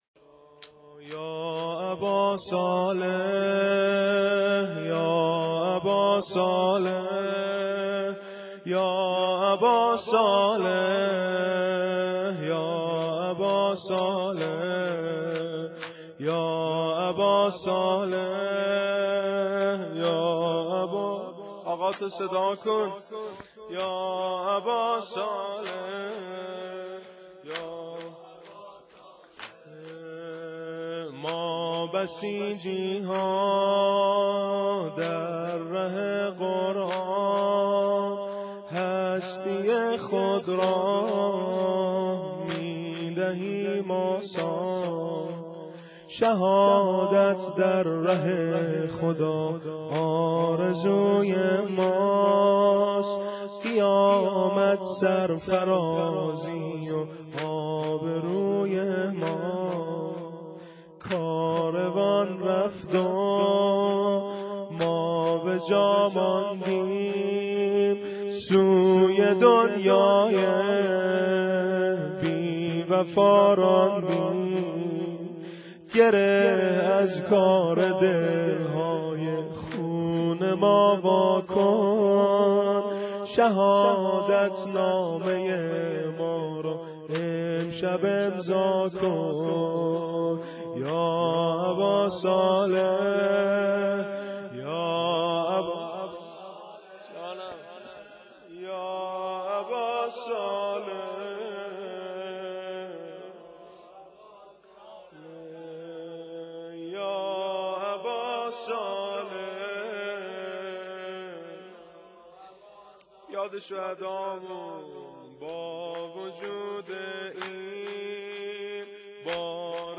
شور شهدایی